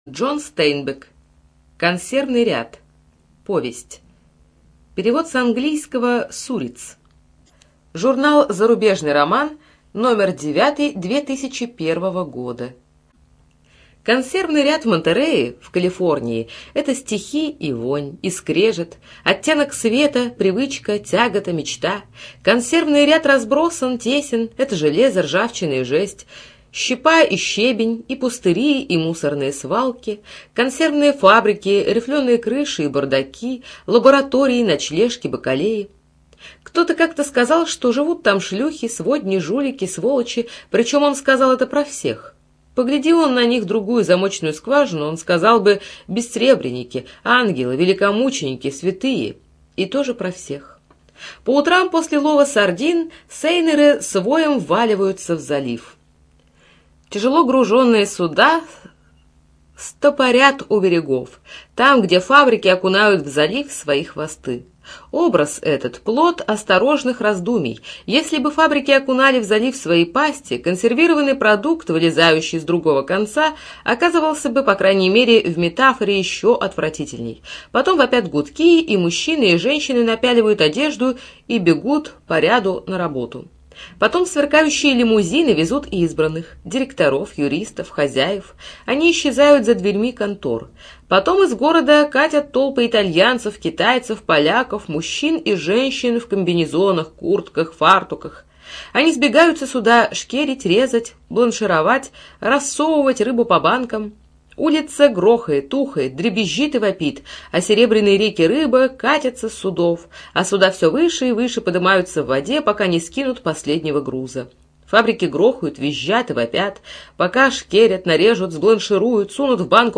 ЖанрКлассическая проза
Студия звукозаписиКемеровская областная специальная библиотека для незрячих и слабовидящих